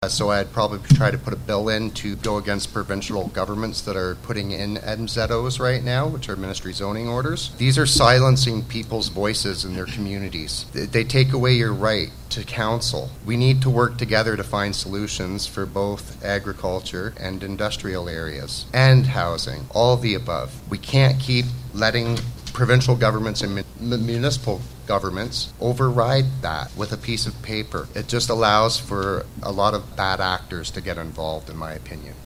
All-candidates debate, hosted by the Simcoe and District Chamber of Commerce